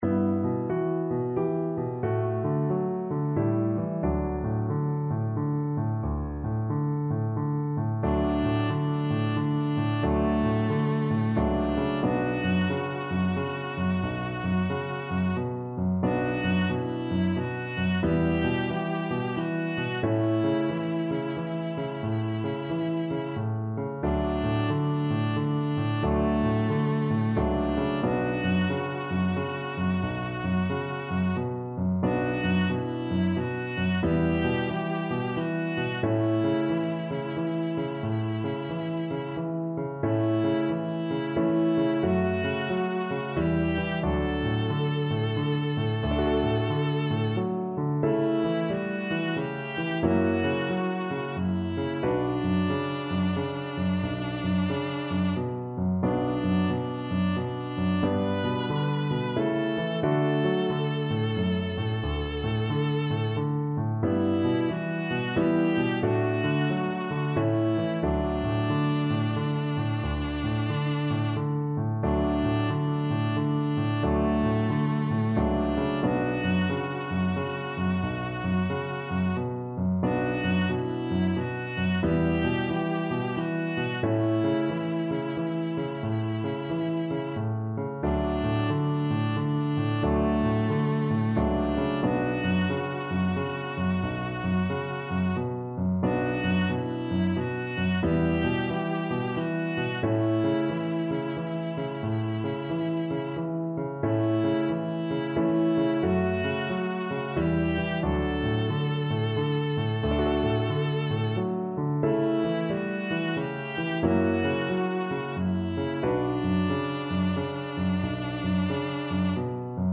Viola
3/4 (View more 3/4 Music)
D major (Sounding Pitch) (View more D major Music for Viola )
=90 Andante, gentle swing
Traditional (View more Traditional Viola Music)